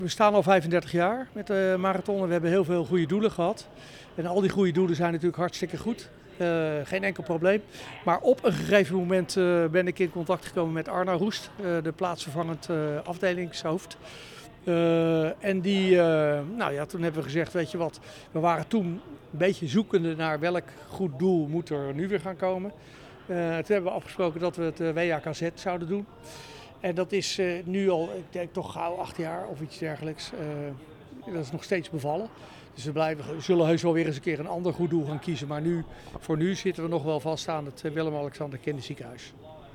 in gesprek met marathon directeur